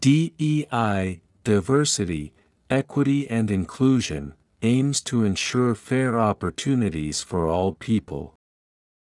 １文ずつ区切ったスロー音声を再生し、文字を見ずにリピートしましょう。